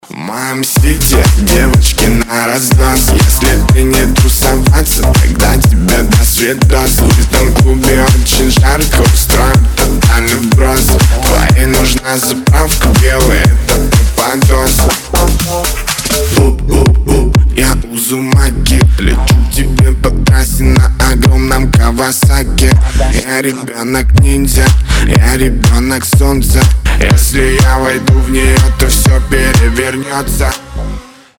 • Качество: 320, Stereo
громкие
house